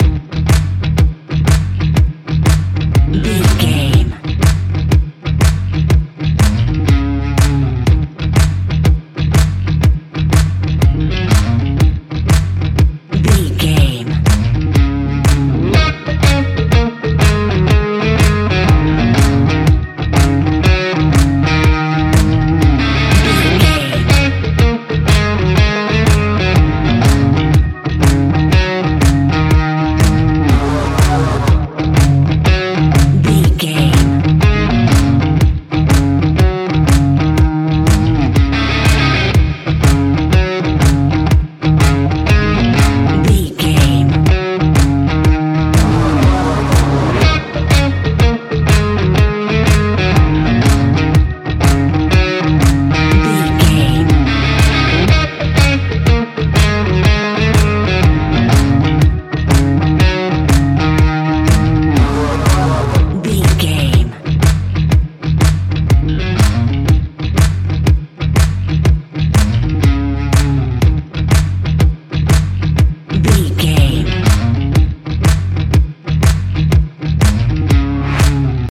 Uplifting
Aeolian/Minor
E♭
intense